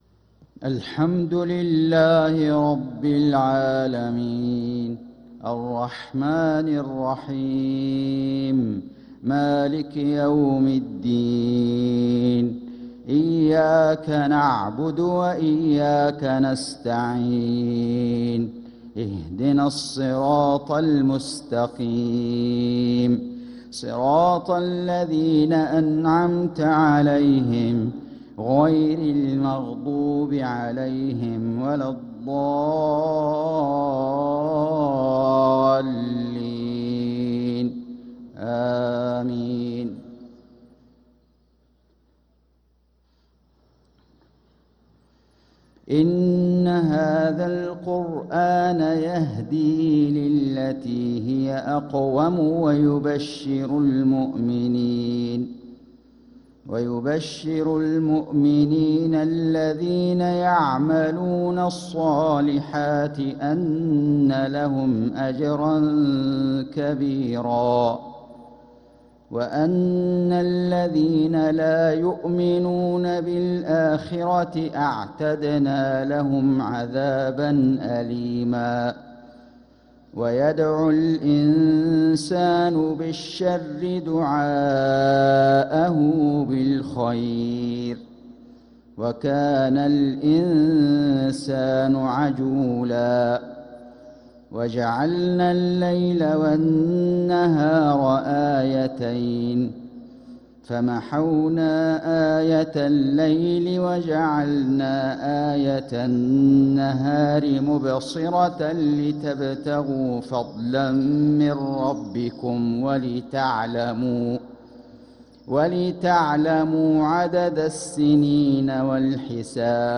صلاة المغرب للقارئ فيصل غزاوي 13 صفر 1446 هـ
تِلَاوَات الْحَرَمَيْن .